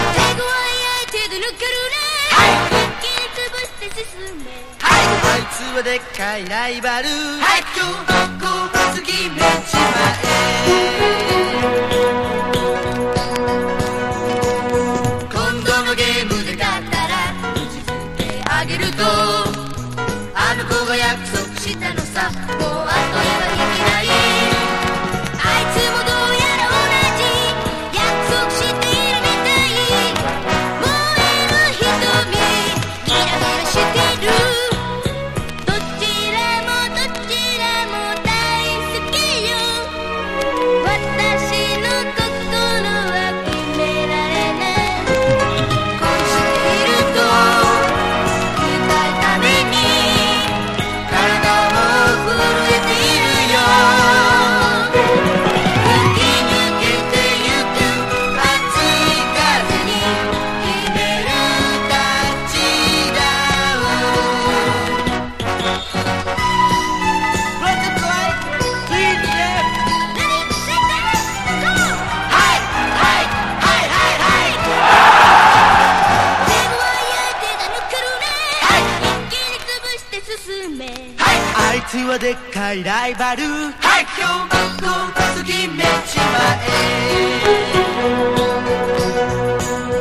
# POP# 和モノ / ポピュラー# 70-80’S アイドル